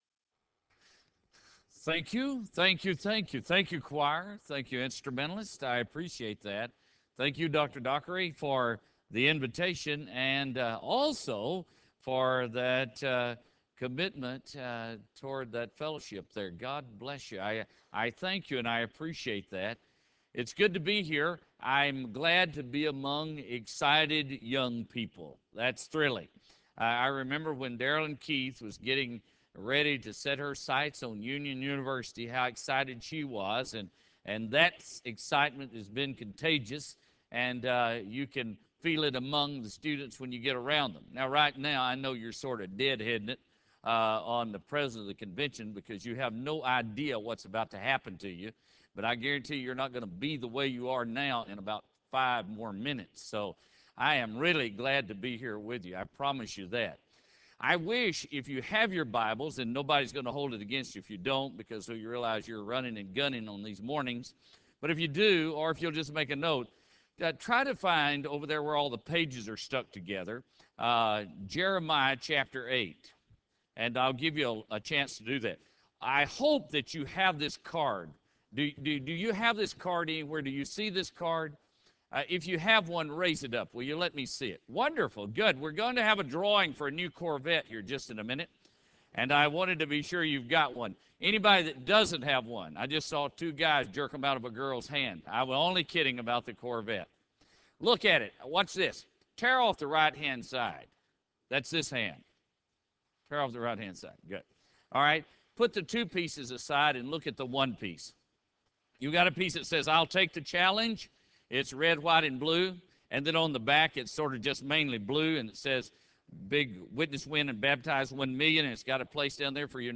Chapel Service: Bobby Welch
Chapel Service: Bobby Welch Bobby Welch , President, Southern Baptist Convention Address: Jeremiah 8:20 Recording Date: Apr 13, 2005, 10:00 a.m. Length: 33.37 Format(s): WindowsMedia Audio ; RealAudio ;